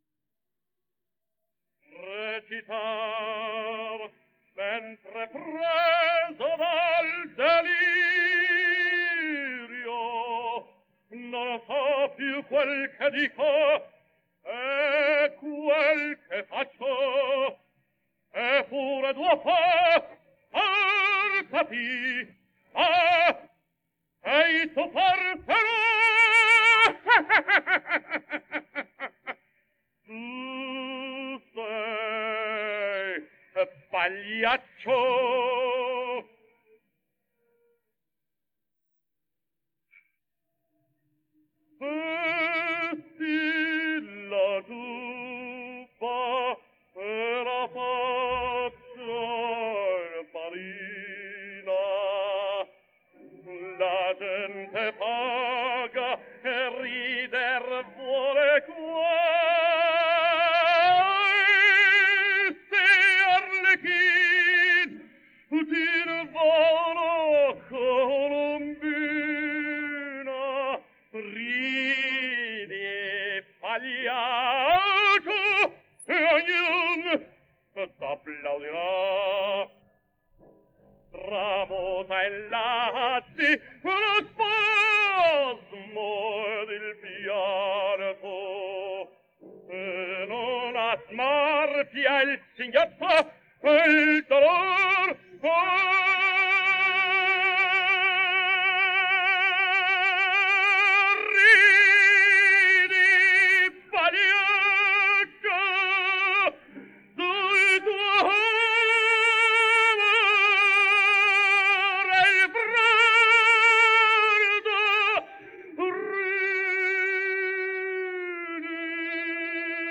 Index of /publications/papers/dafx-babe2/media/restored_recordings/caruso_giubba